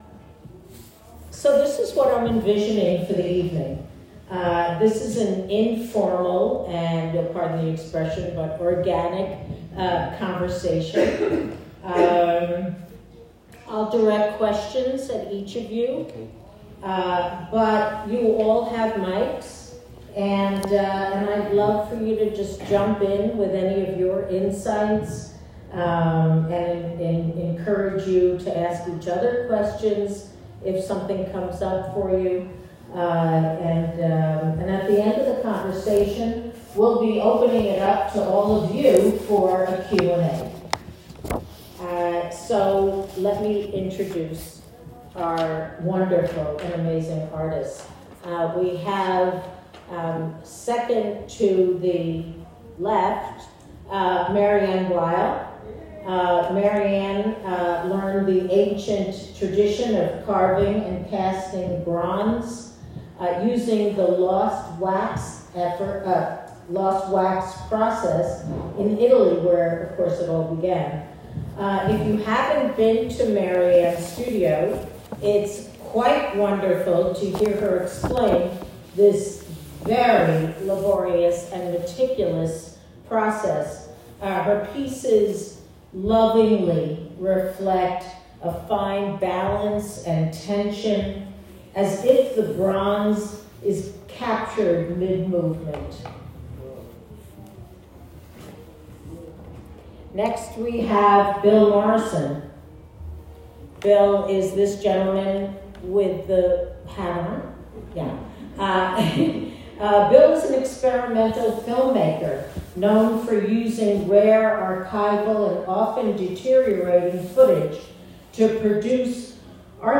Join North Fork Contemporary for a panel discussion about the delicate interplay between an artist’s inner world and their creative expression. Together, we’ll explore the delicate dance between inspiration and execution, emotion and expression, chaos and completion.